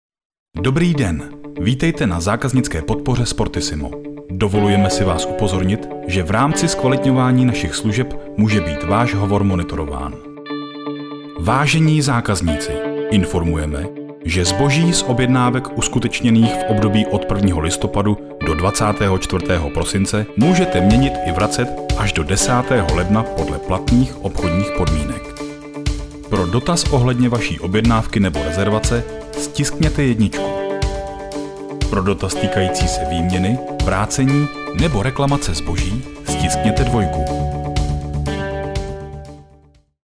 Mužský voiceover - hlas do krátkých reklamních spotů!
Součástí každého jobu je i základní postprodukce, tedy odstranění nádechů, filtrování nežádoucích frekvencí a ekvalizace a nastavení exportu minimálně 96kHz/24bit, okolo -6dB, jestli se nedohodneme jinak.
SPORTISIMO IVR.mp3